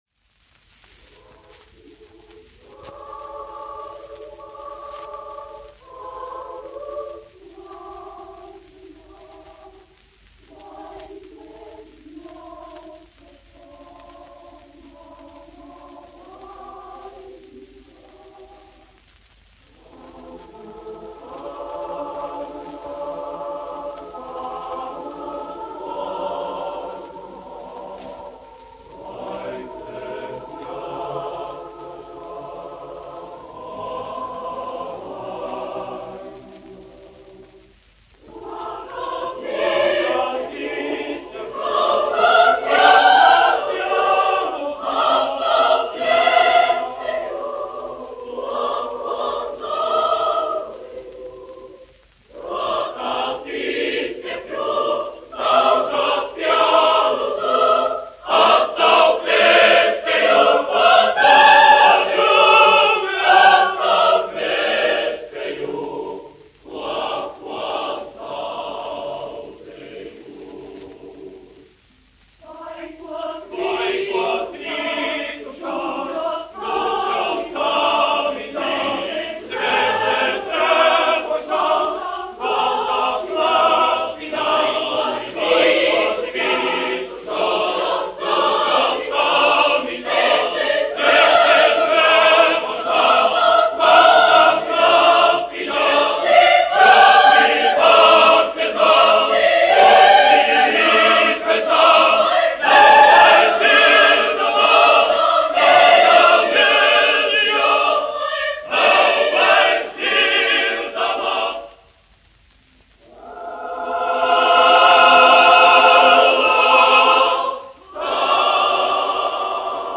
Reitera koris, izpildītājs
1 skpl. : analogs, 78 apgr/min, mono ; 25 cm
Kori (jauktie)
Skaņuplate